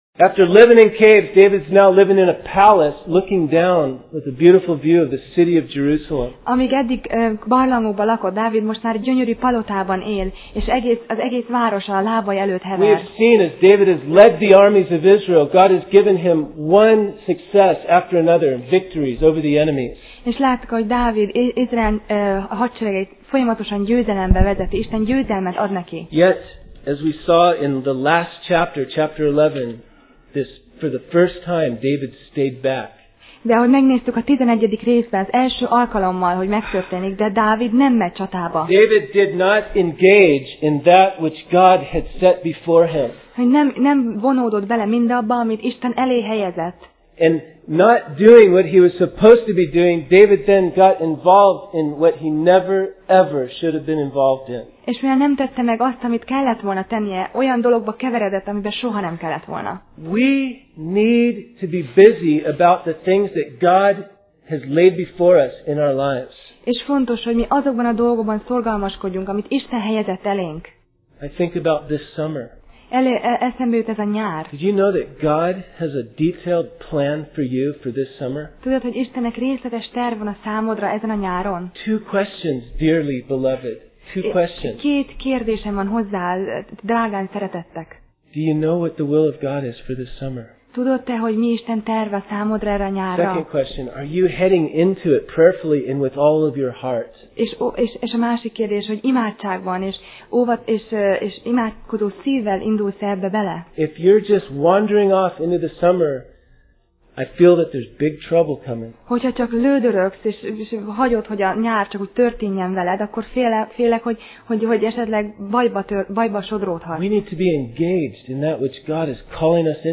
2Sámuel Passage: 2Sámuel (2Samuel) 12:1-14 Alkalom: Szerda Este